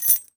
foley_keys_belt_metal_jingle_08.wav